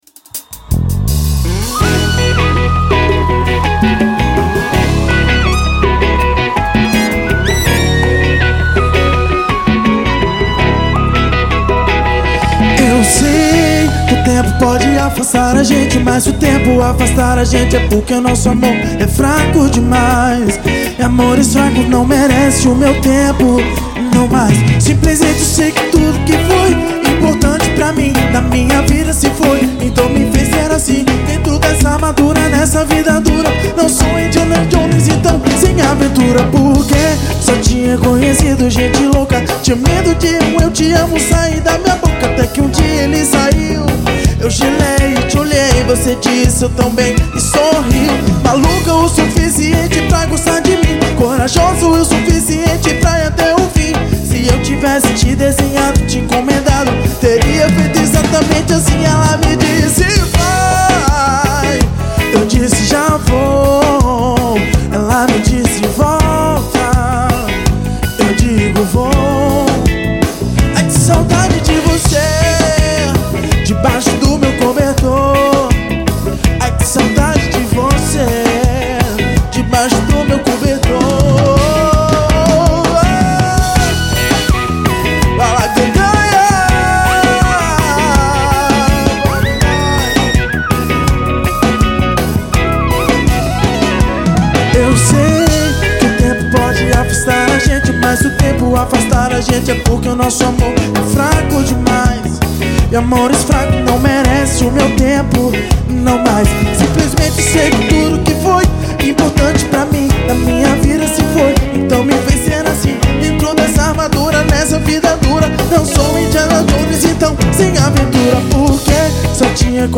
Cd promocional ao vivo venda proibida.